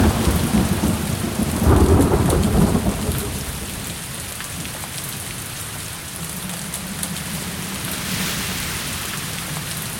Bruitage – Orage – Le Studio JeeeP Prod
Bruitage haute qualité créé au Studio.
Orage.mp3